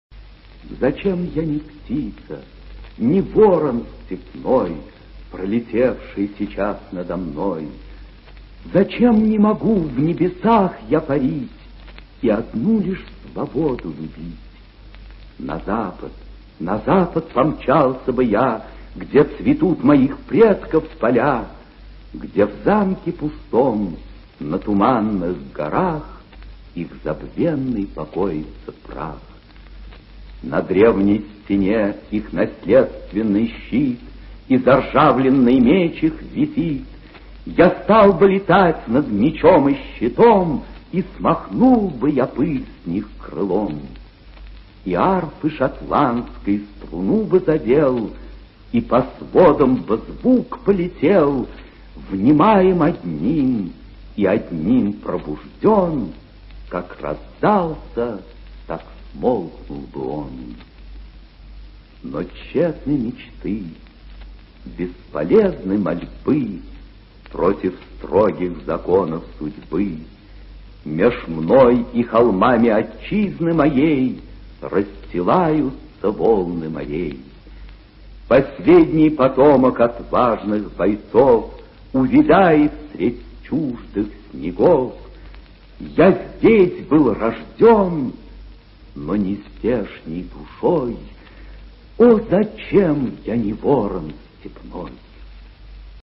Прослушивание аудиозаписи стихотворения с сайта «Старое радио». Исполнитель В. Аксенов.